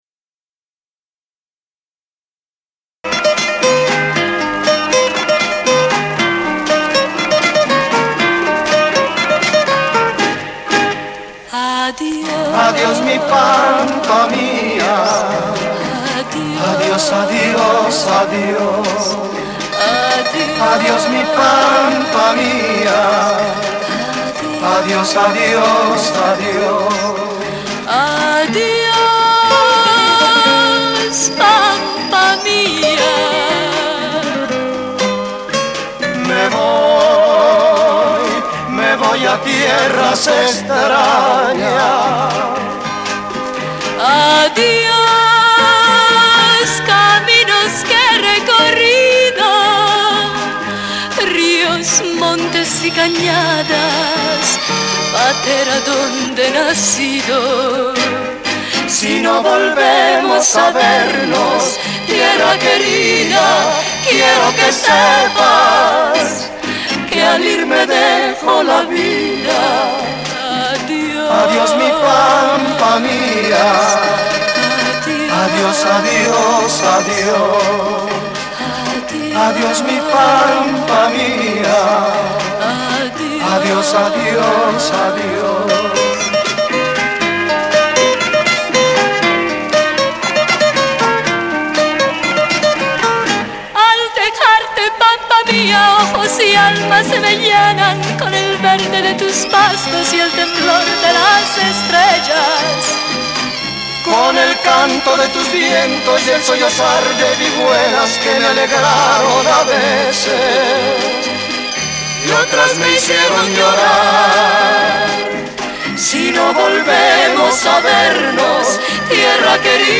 the wonderful voices and guitars of the famous trio